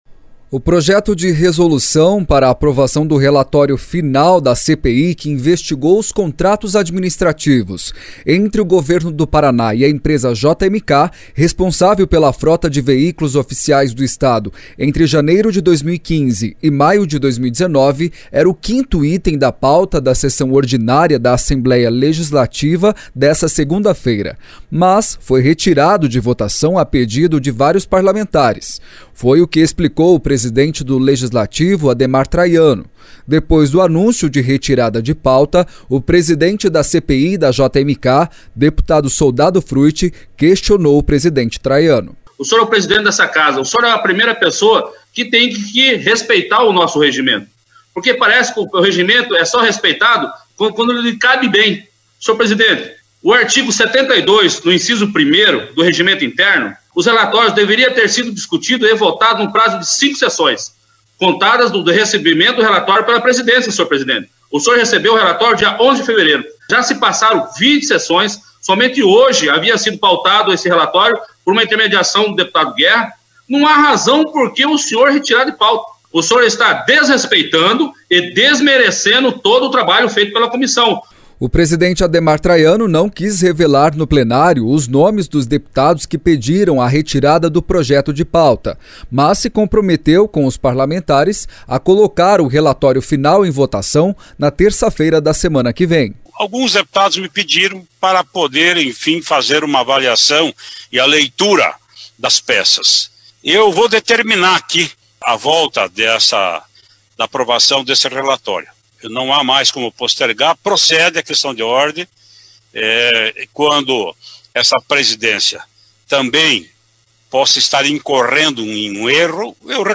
Foi o que explicou o presidente do Legislativo Ademar Traiano. Depois do anúncio de retirada de pauta, o presidente da CPI da JMK, deputado Soldado Fruet, questionou o presidente Traiano.